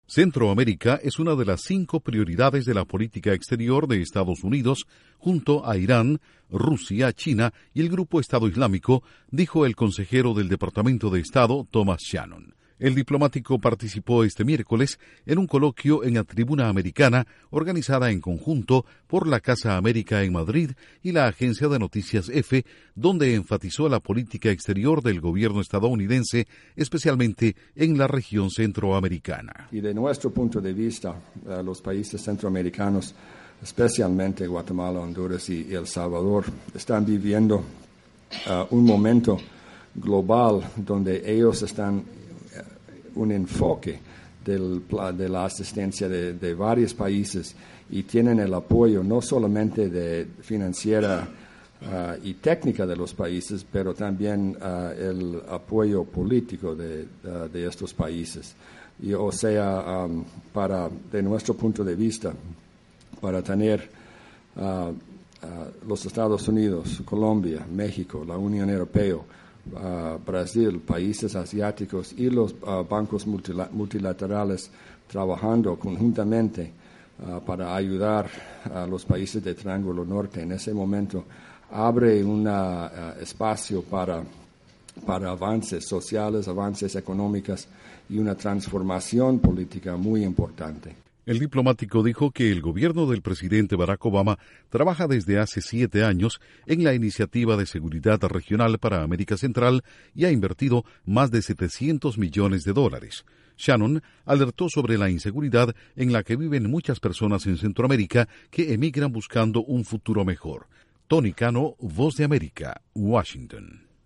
Duración: 1:54 (Versión Corta) Con audio de Thomas Shannon/Departamento de Estado